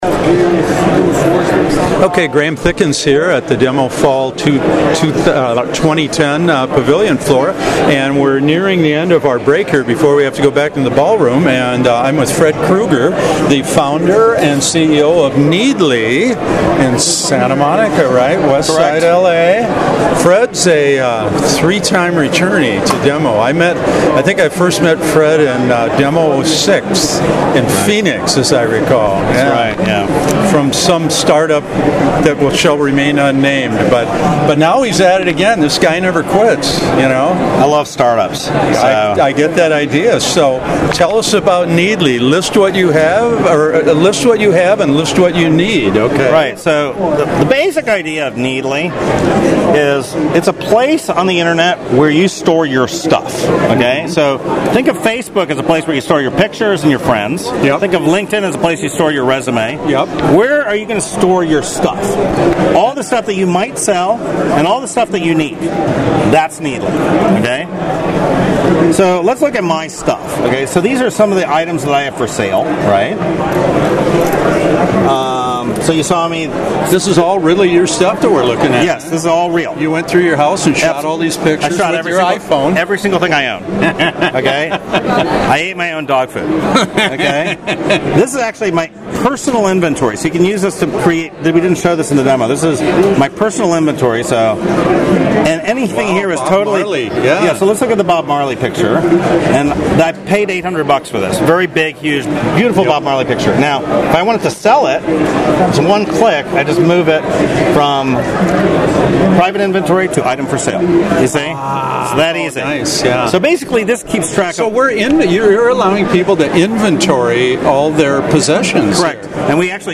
Needly-DEMOfall2010.mp3